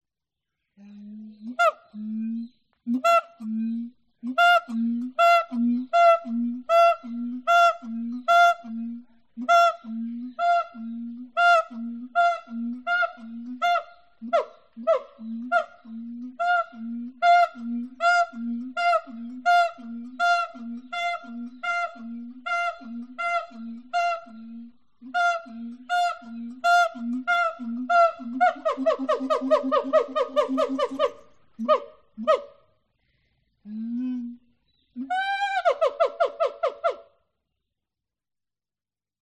• Качество: высокое
Волшебный звук пения сиаманга